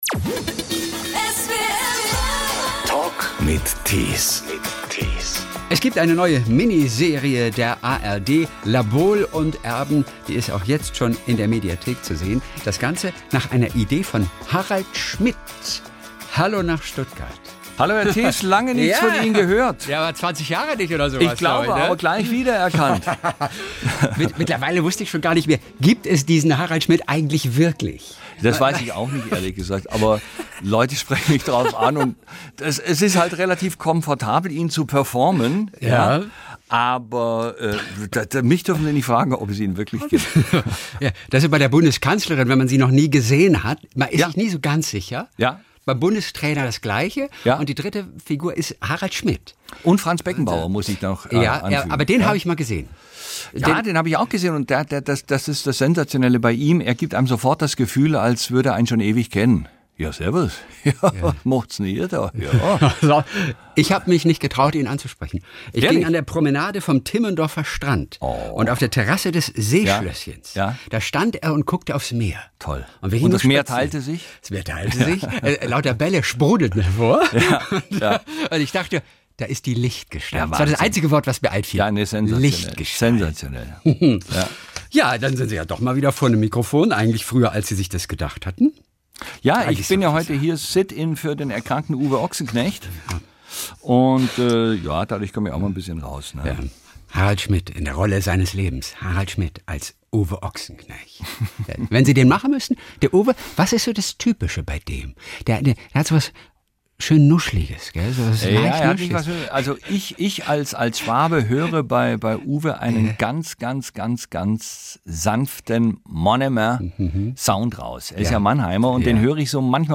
Der Talk in SWR 3